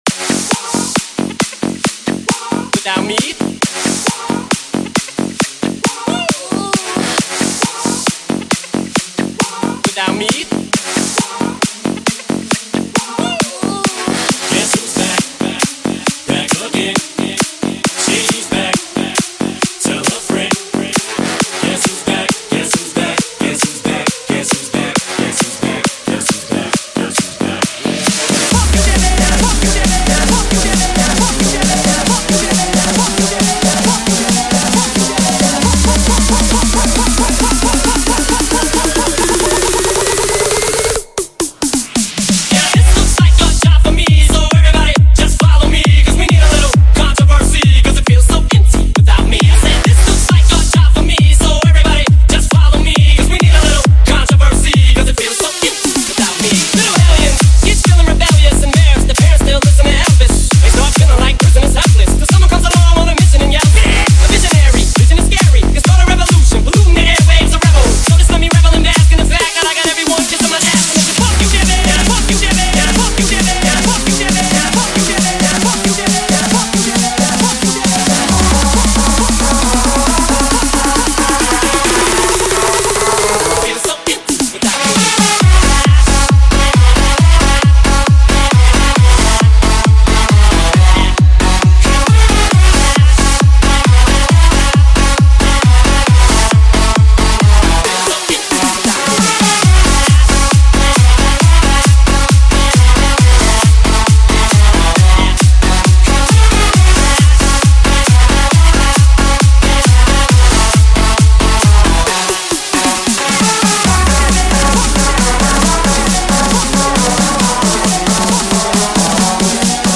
ریمیکس دوم